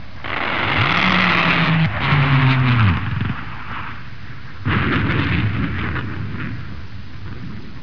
جلوه های صوتی
دانلود صدای طیاره 37 از ساعد نیوز با لینک مستقیم و کیفیت بالا